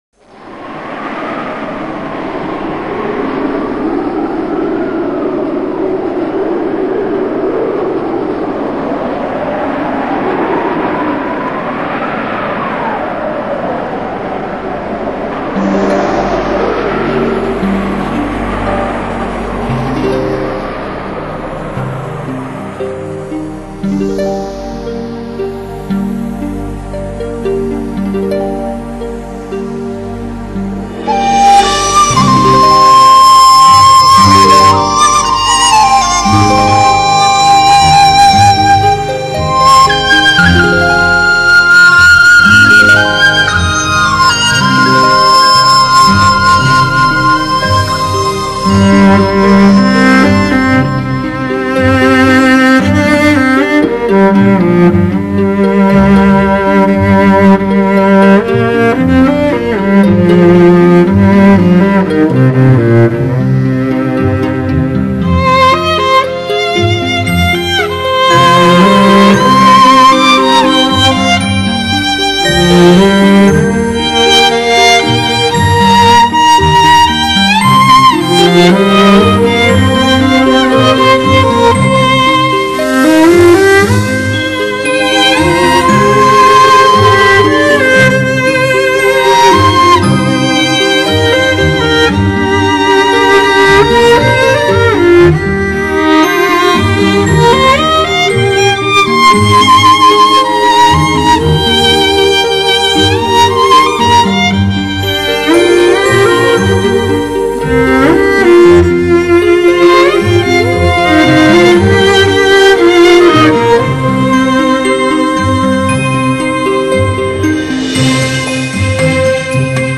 录制更是出色